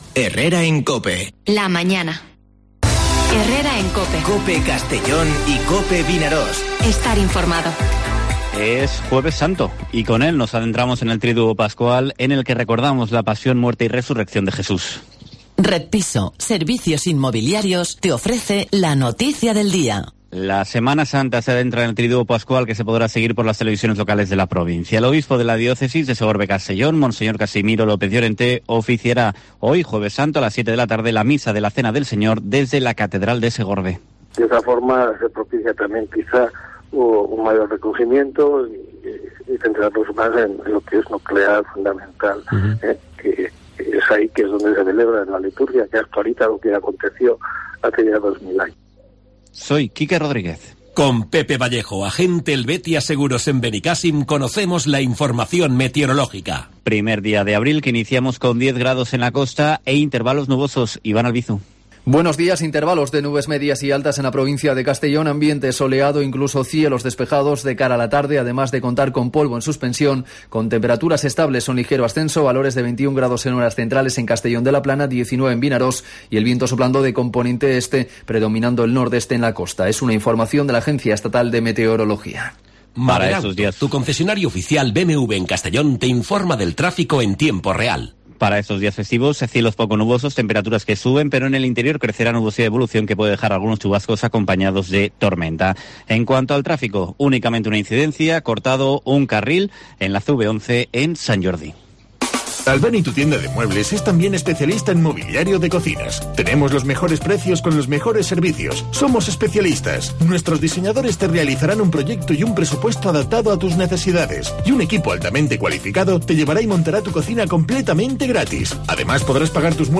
Informativo Herrera en COPE en la provincia de Castellón (01/04/2021)